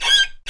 Menu Grow Taller Sound Effect